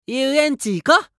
青年ボイス～シチュエーションボイス～